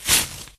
增加报纸撕裂音效
newspaperRip.ogg